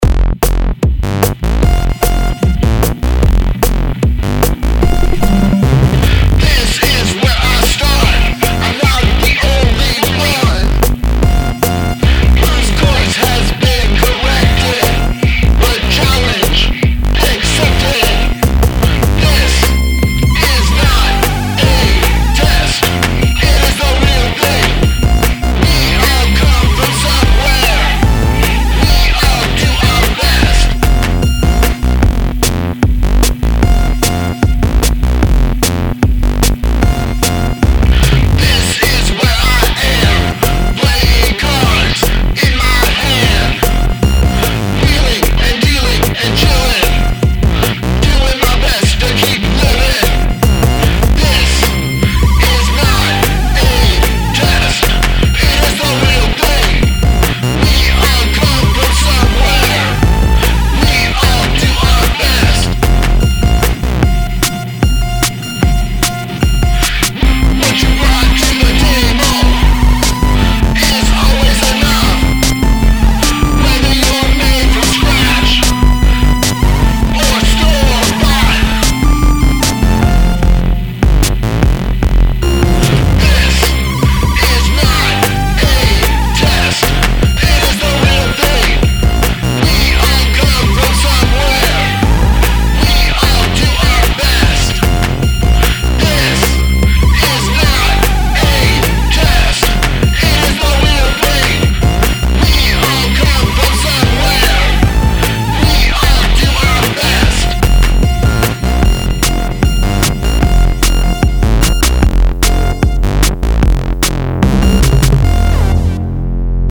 I'm talkin' 'bout that big fat farty bass line.